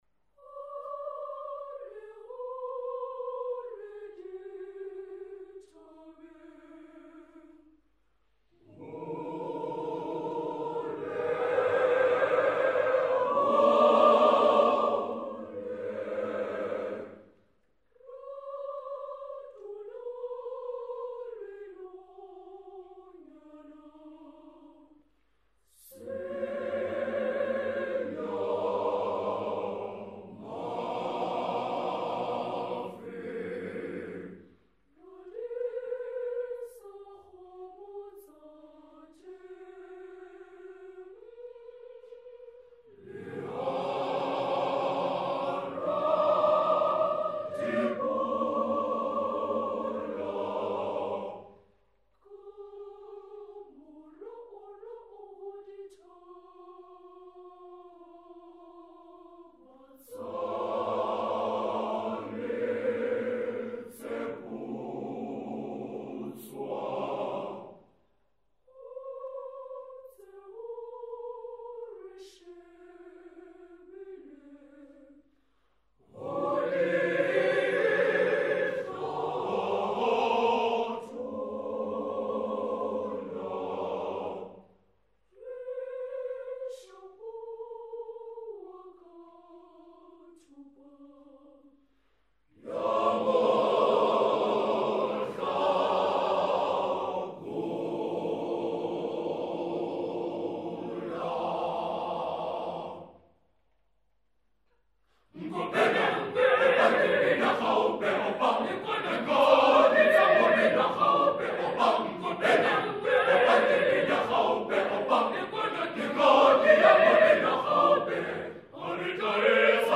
choir SSATB
Traditional style | traditional life